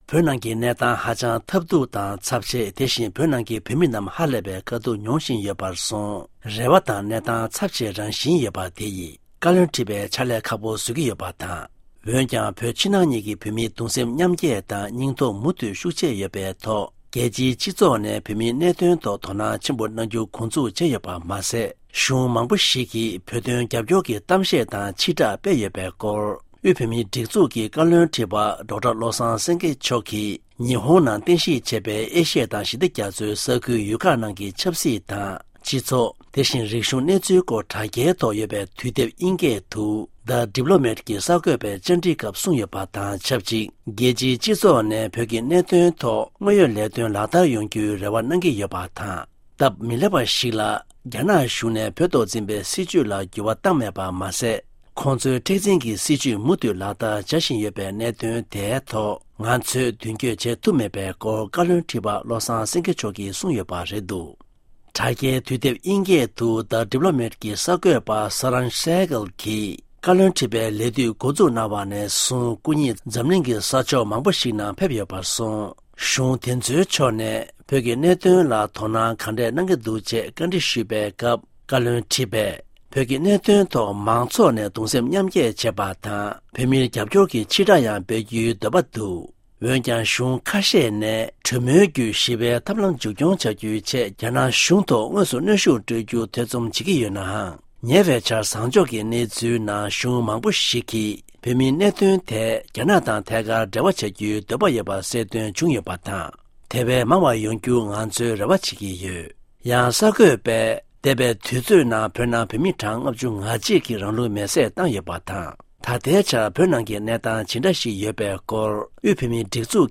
བོད་མིའི་སྒྲིག་འཛུགས་ཀྱི་བཀའ་བློན་ཁྲི་པ་བློ་བཟང་སེང་གེ་མཆོག་གིས་གསར་འགོད་པས་གནས་འདྲི་ཞུས་པའི་སྐབས་གསུངས་ཡོད་པའི་སྐོར་